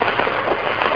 1 channel
static1.mp3